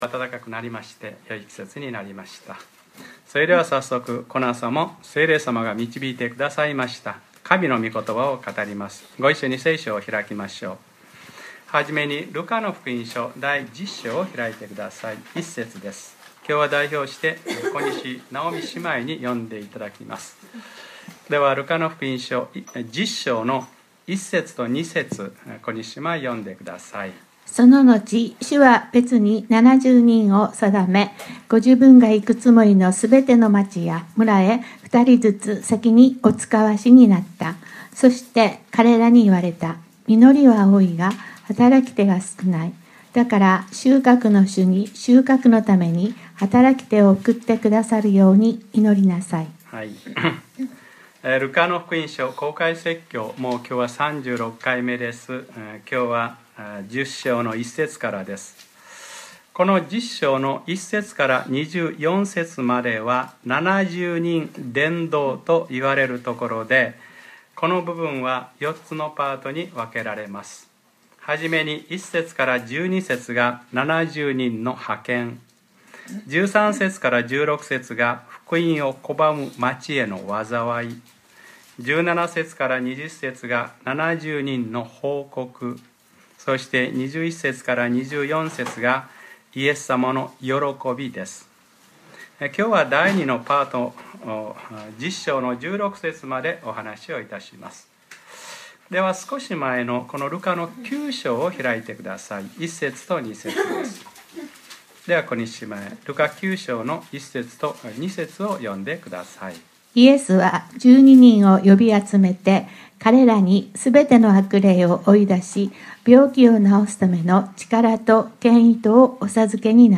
2014年 3月 2日（日）礼拝説教『ルカ-３６：別に７０人を定め』